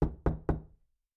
03_书店外黄昏_敲门.wav